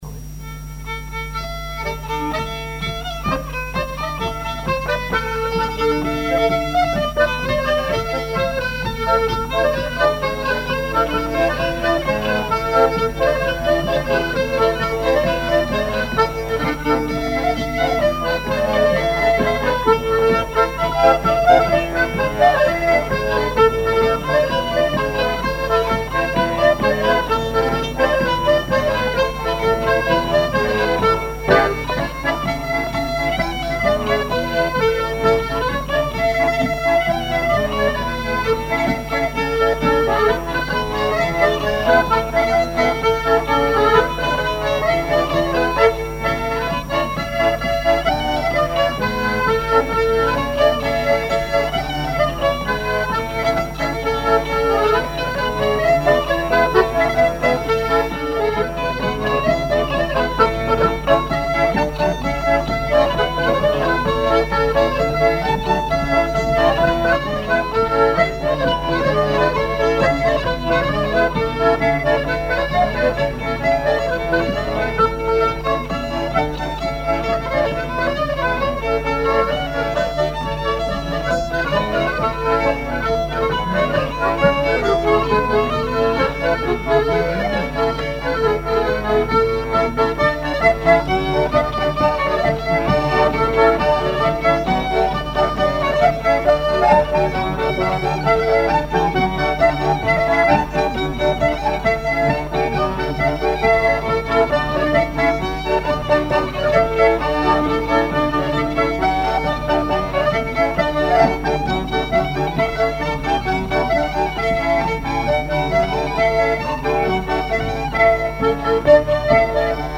danse : branle : courante, maraîchine
collectif de musiciens pour une animation à Sigournais
Pièce musicale inédite